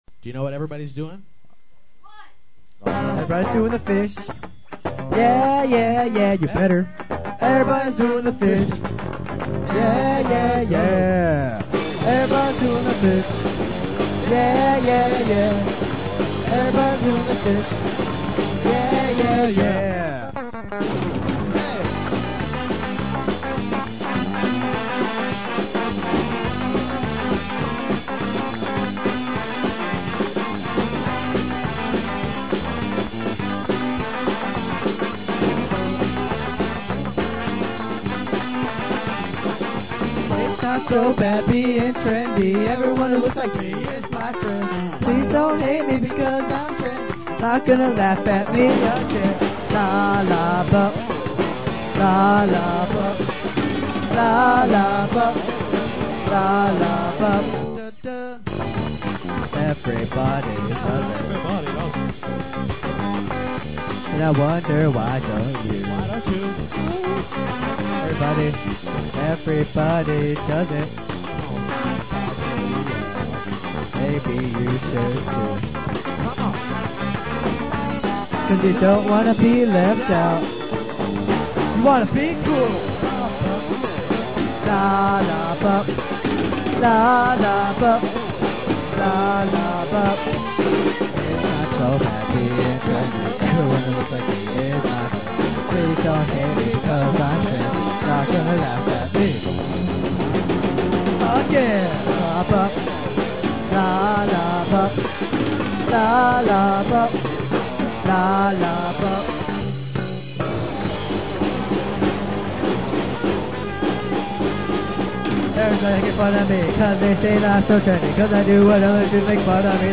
Guitar
Drums/Back-up Vocals
Bass/Vocals
Keyboards